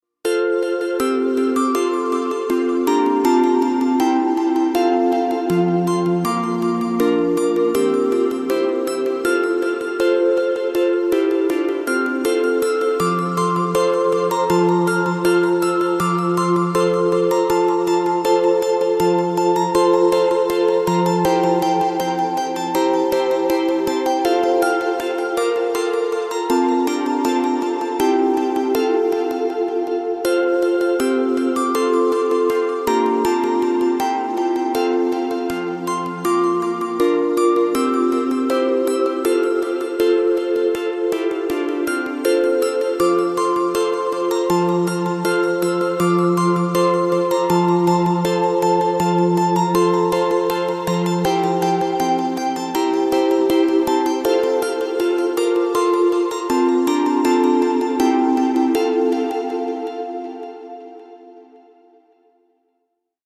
Genres: Classical Music
Tempo: 164 bpm